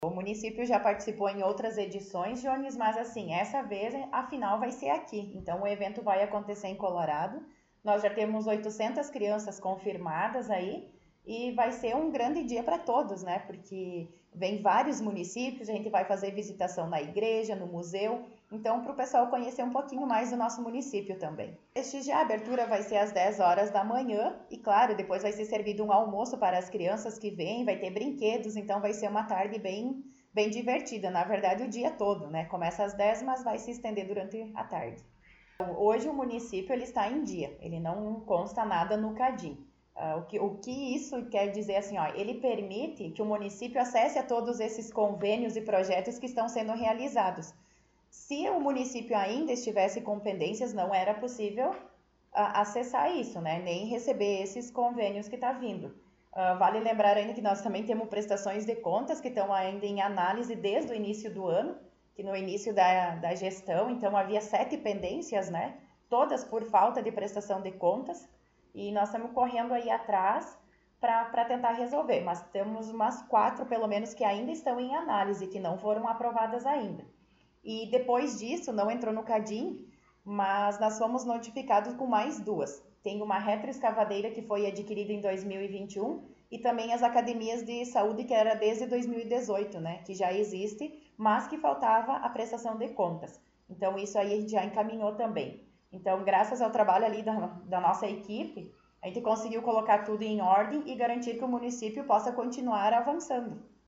Secretária Municipal de Planejamento concedeu entrevista